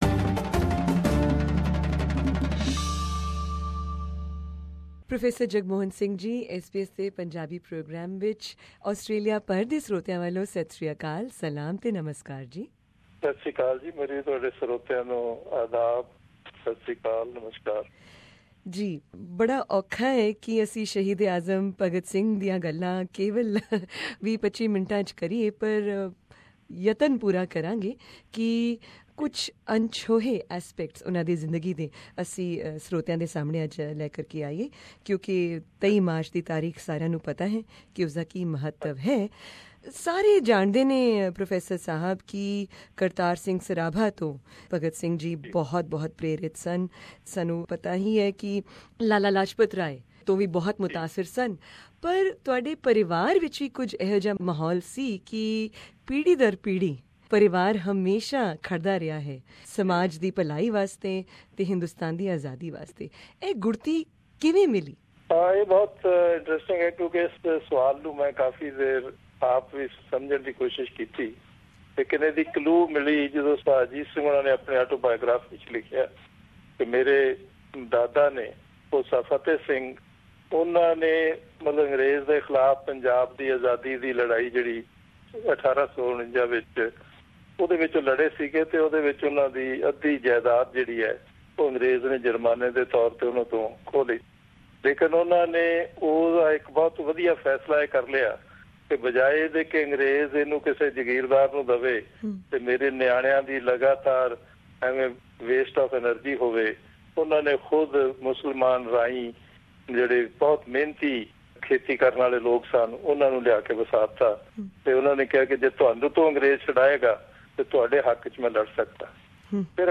Click on the audio player above to hear the first part of this interview, and the second part can be heard from the link below.